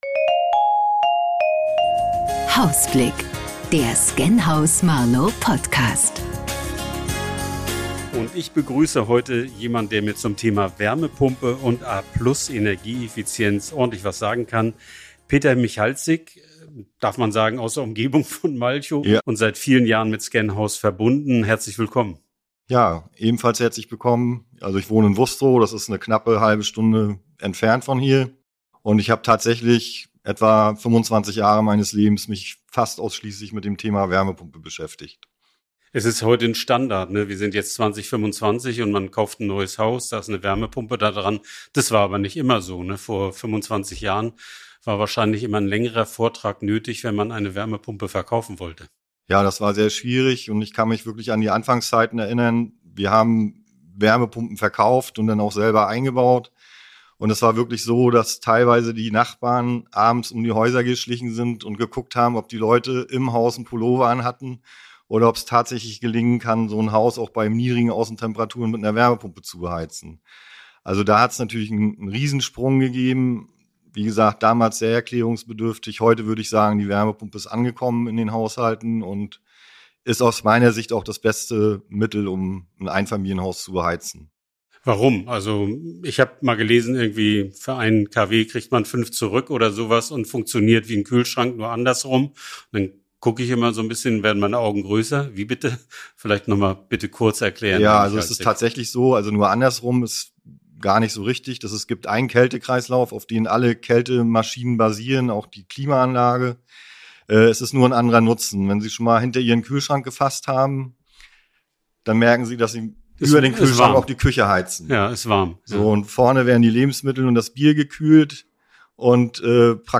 Der Hausblick zum Thema Wärmepumpen – im Gespräch mit den Experten Heute dreht sich beim Hausblick alles um eine der wichtigsten Heiztechnologien der Zukunft: die Wärmepumpe.